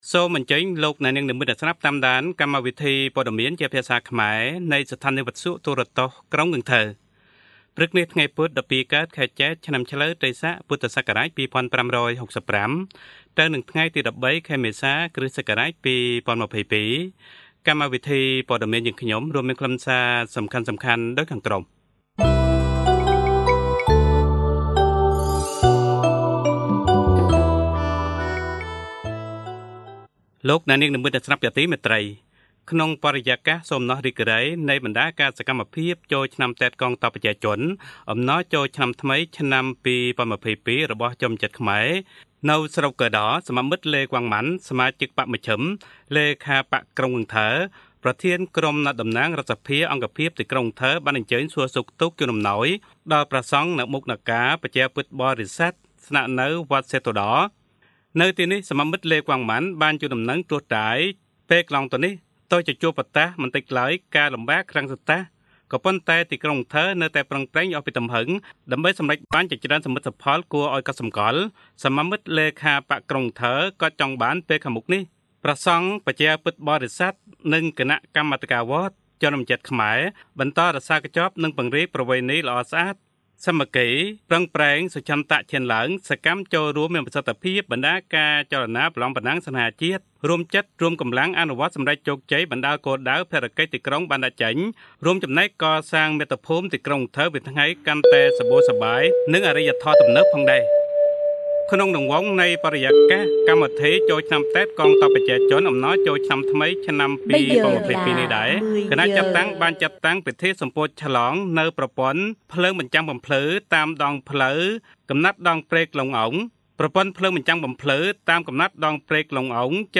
Bản tin tiếng Khmer sáng 13/4/2022
Kính mời quý thính giả nghe Bản tin tiếng Khmer sáng ngày 13/4/2022 của Đài Phát thanh và Truyền hình Thành phố Cần Thơ.